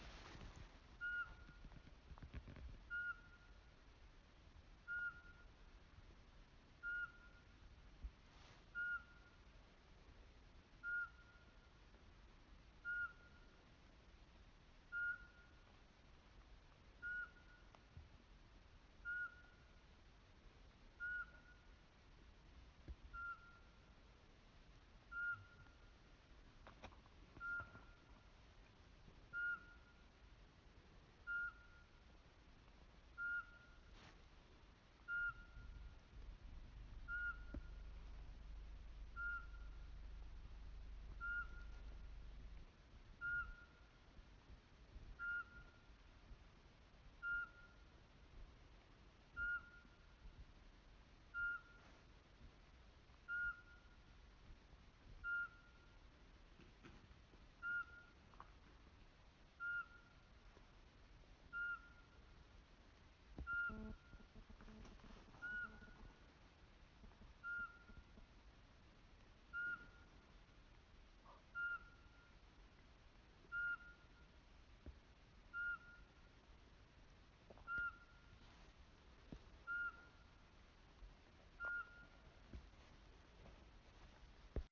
Uggleexkursion, lördagen den 11 mars.
En Sparvuggla kom visslande flygande och satte sig i ett träd inom synhåll. Strax därefter flög den längre in i skogen och satte igång ett ihärdigt visslande som pågick oavbrutet i ca två minuter.
sparvuggla.wav